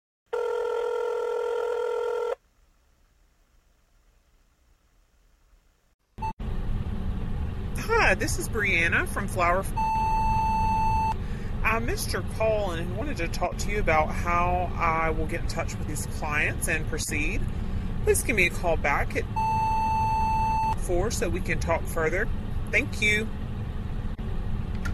The Voice Lead Generator drops your promo message right into voicemail boxes, no cold calling needed!